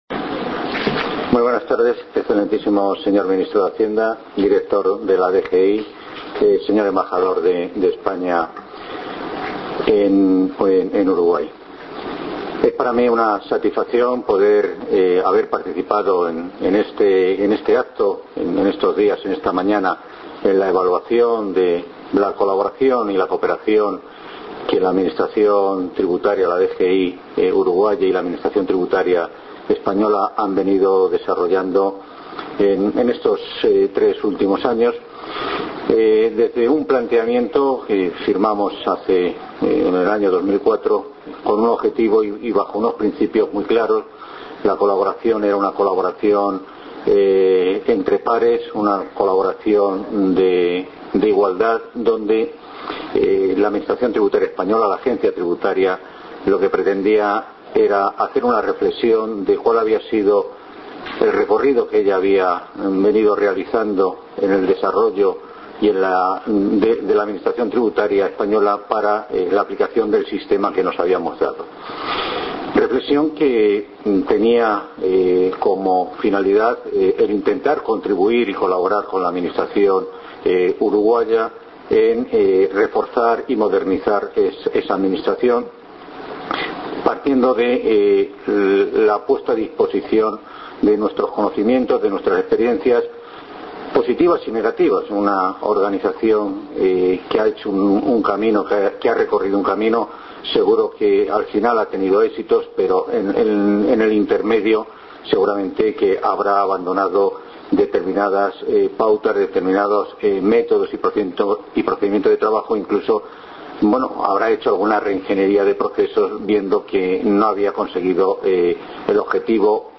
Palabras del representante de la Agencia Estatal de Administración Tributaria de España, Luis Pedroche Rojo, en conferencia de prensa en la sede de la Dirección General Impositiva.